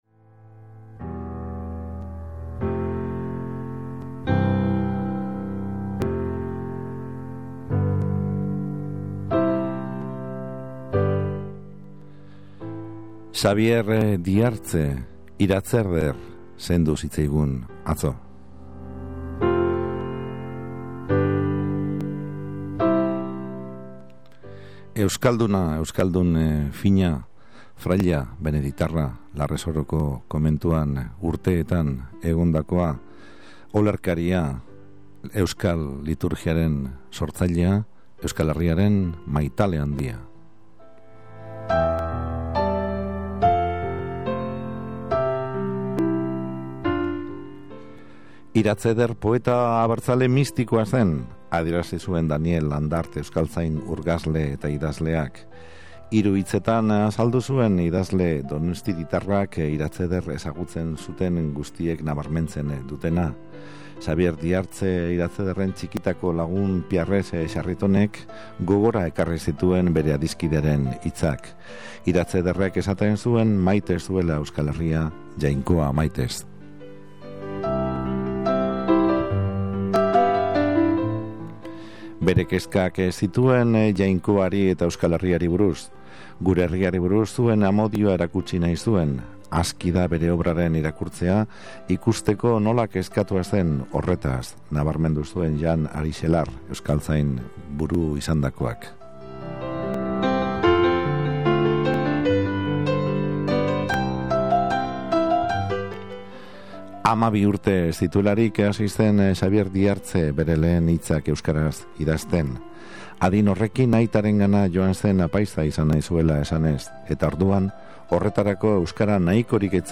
Bere biografiaz zenbait datu eman ostean, berak idatziriko “Ixtorio bat” izeneko ipuinaren irakurtaldia egin dugu omenaldi gisa.